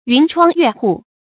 云窗月戶 注音： ㄧㄨㄣˊ ㄔㄨㄤ ㄩㄝˋ ㄏㄨˋ 讀音讀法： 意思解釋： 指華美幽靜的居處。